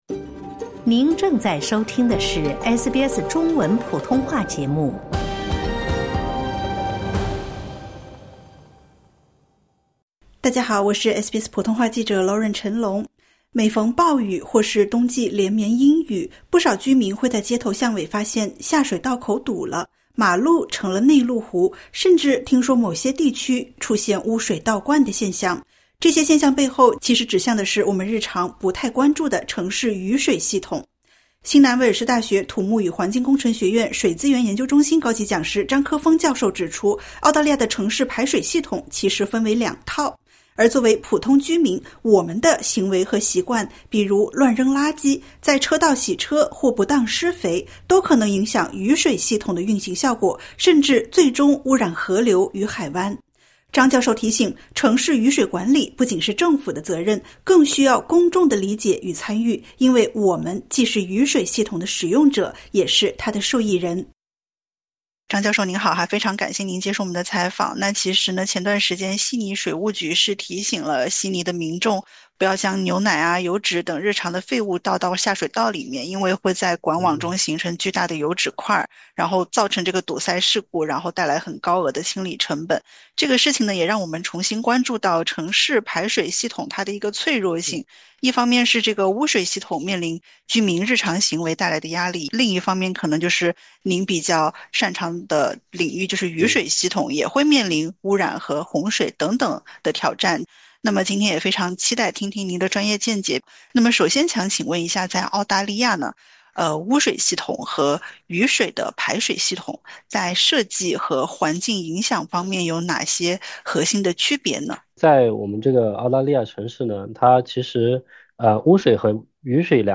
专家提醒，城市雨水管理不仅是政府的责任，更需要公众的理解与参与，“因为我们既是雨水系统的使用者，也是它的受益人”。点击 ▶ 收听完整采访。